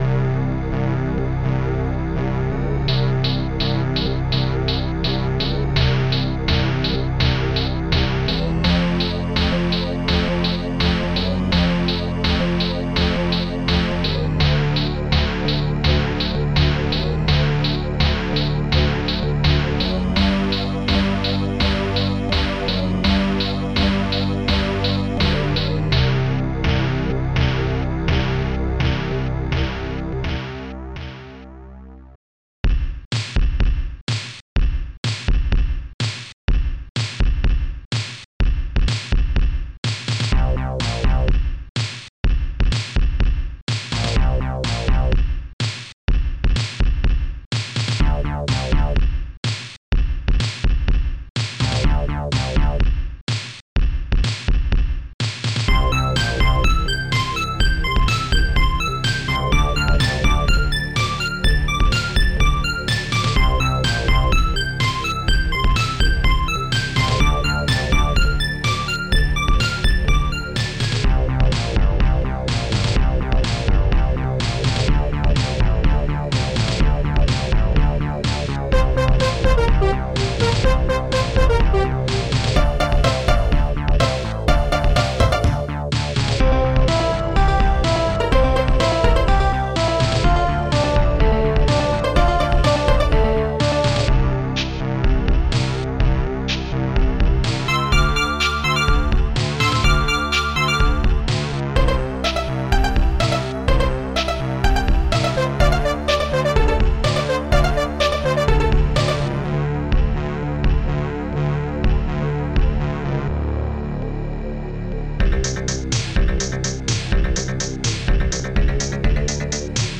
wowbass
shaker
popsnare2
hihat2
bassdrum3
hallbrass
funkbass
steinway
korgstring